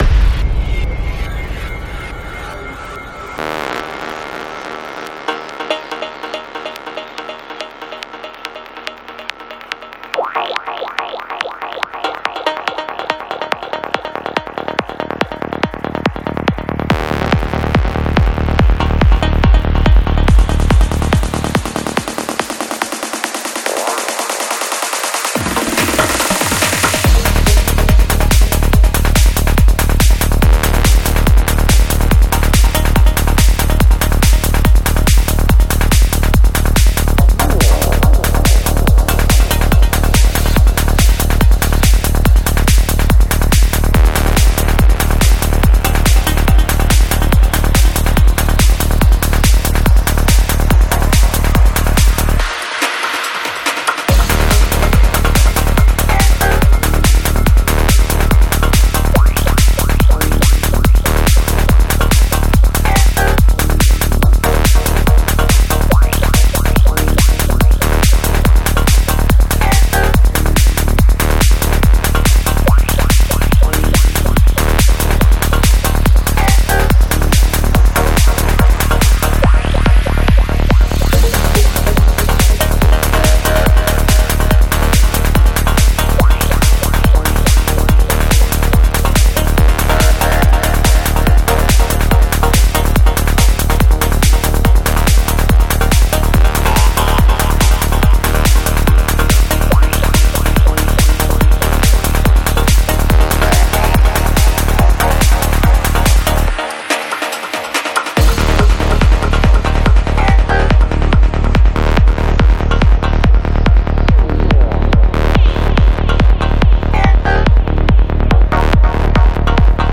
Жанр: Psychedelic
Psy-Trance Скачать 6.58 Мб 0 0 0